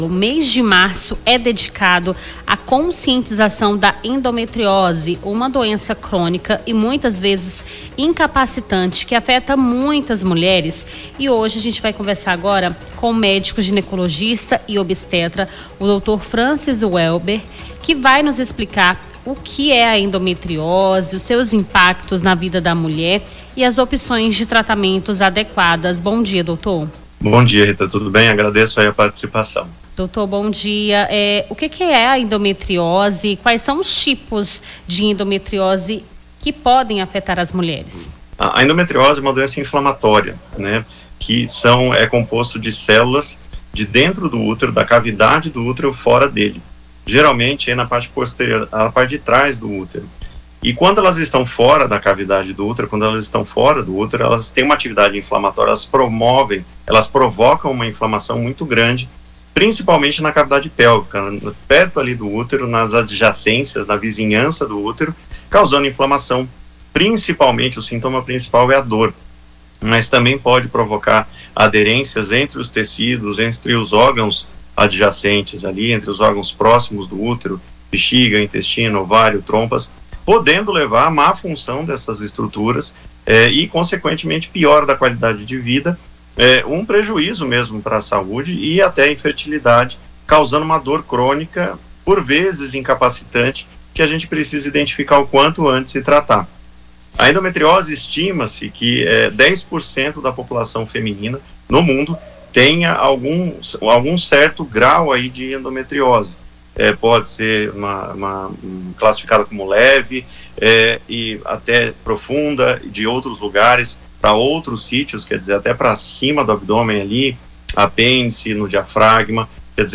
Nome do Artista - CENSURA - ENTREVISTA MARÇO AMARELO (25-03-25).mp3